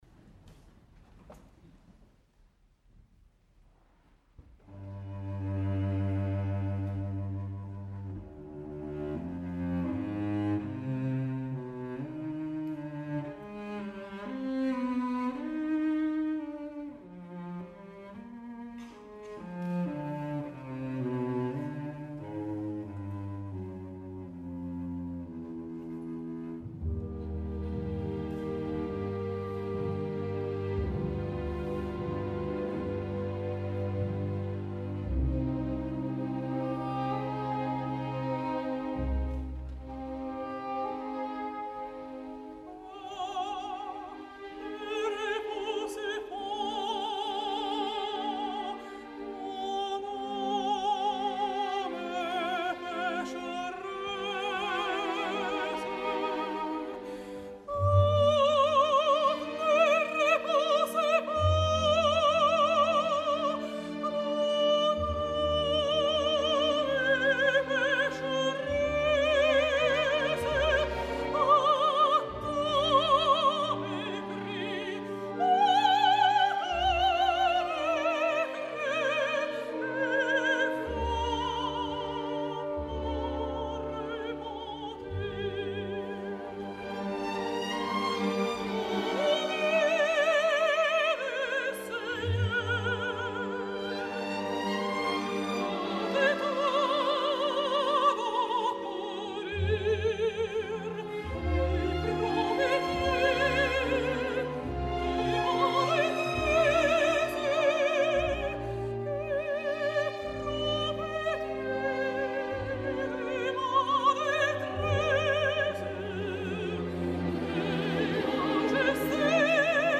al Victoria Hall de Ginebra